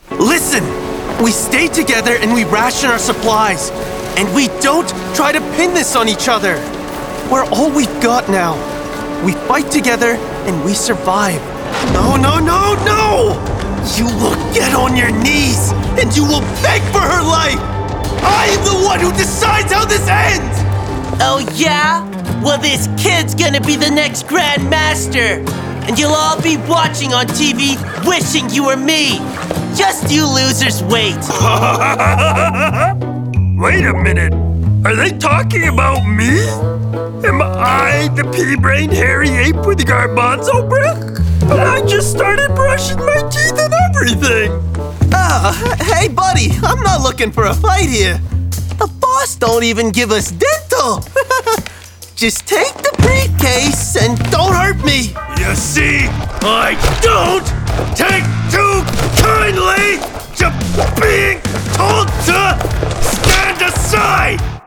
Animação
Microfone: Neumann U87ai
Tratamento acústico: Broadcast Studio Room